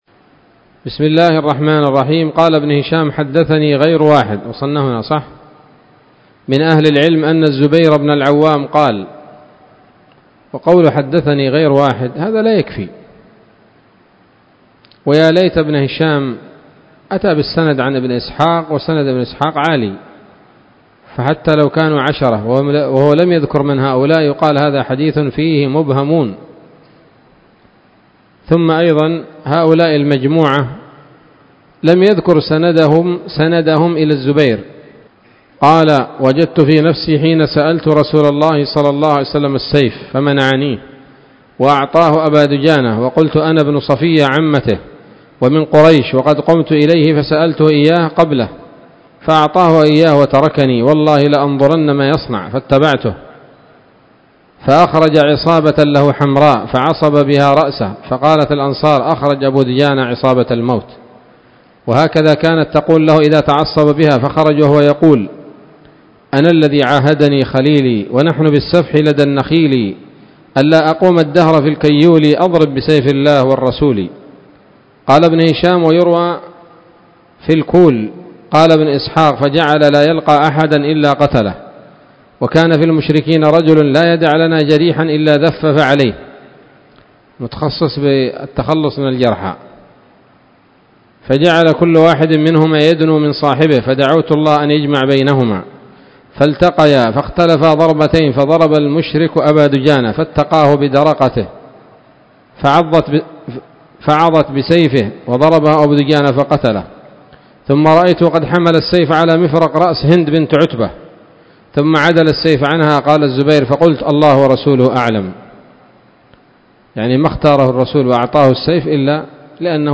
الدرس السادس والخمسون بعد المائة من التعليق على كتاب السيرة النبوية لابن هشام